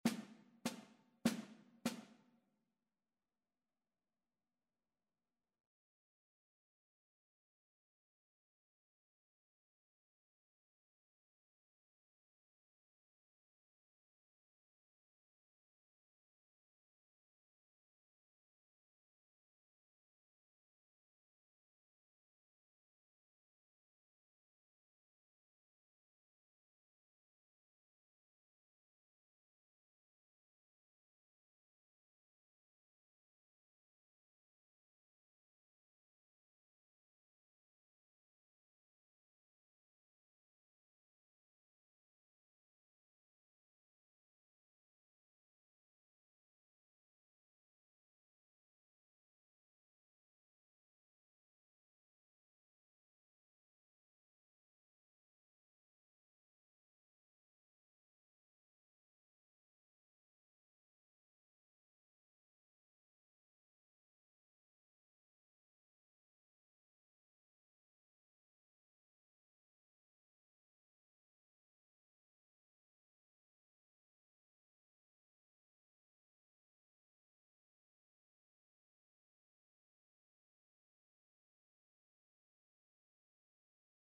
Burgmüller: Cicha skarga (na flet i fortepian)
Symulacja akompaniamentu